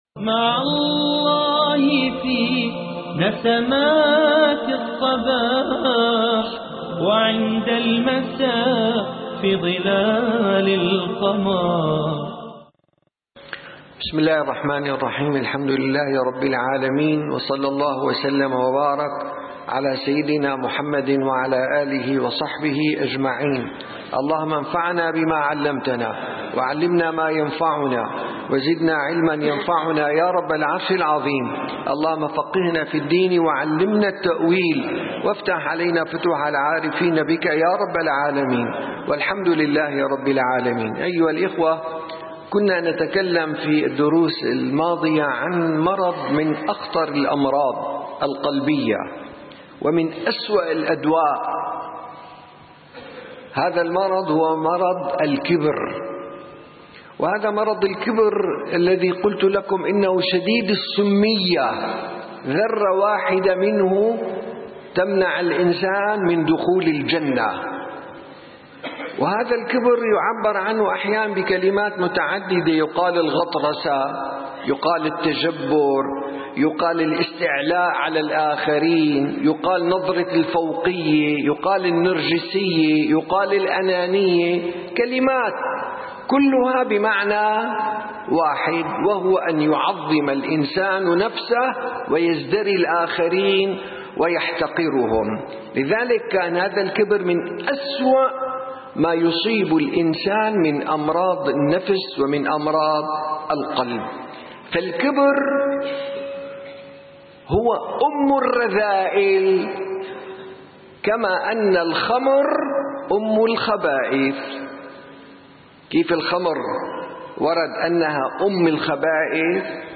14 - درس جلسة الصفا: من أسباب الكبر المنصب والجاه